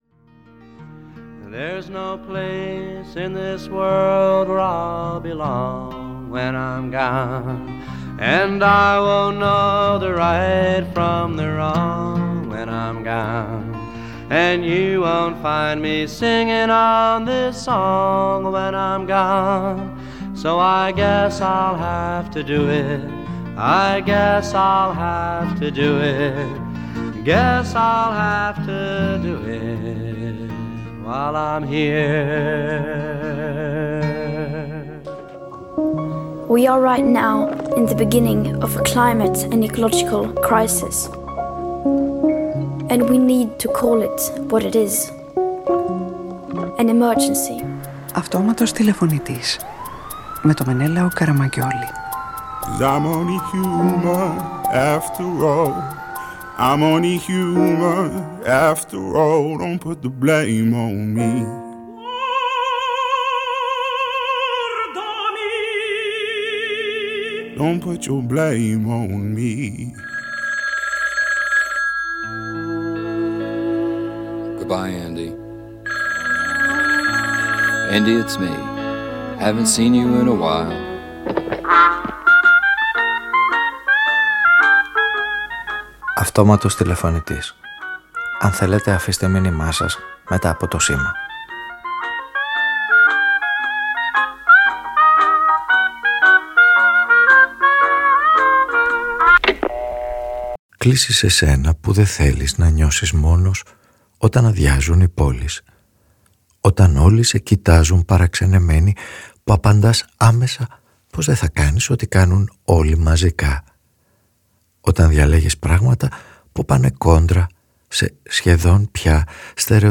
Ο ήρωας της σημερινής ραδιοφωνικής ταινίας δε θέλει να νιώθει μόνος όταν αδειάζουν οι πόλεις και όταν διαλέγει να ζει κόντρα σε στερεοτυπικές δράσεις και μαζικές συμπεριφορές. Διαλέγει για συνεργούς όσους αποδεικνύουν πως το παρόν του καθενός αποτελεί προϊόν μυθοπλασίας, πως τα αντικείμενα καθημερινής χρήσης έχουν δική τους ταυτότητα και ζωή, πως το διαδίκτυο μπορεί να φιλοξενεί ουσιαστικές συναντήσεις και στήνει μαζί τους μια ιστορία που μπορεί να αναστήσει τα παλιά κατεστραμμένα αθλητικά παπούτσια του που νιώθει πως τα χρειάζεται ακόμα.